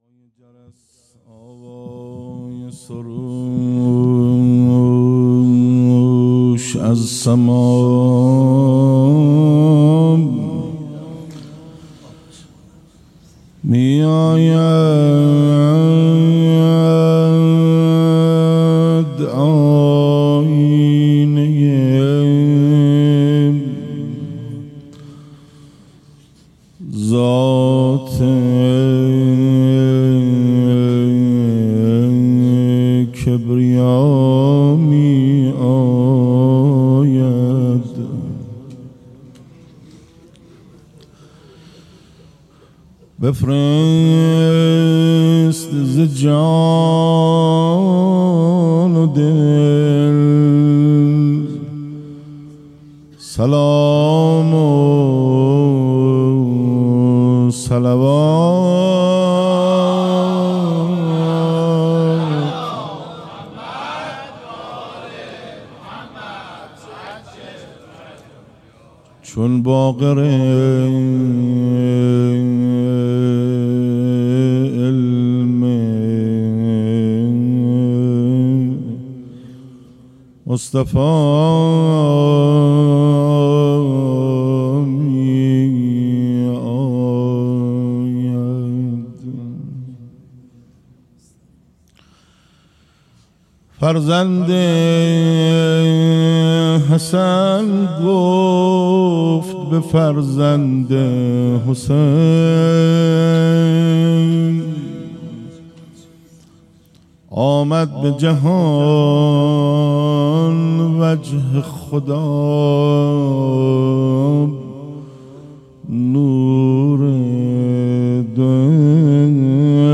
میلاد امام محمد باقر (ع)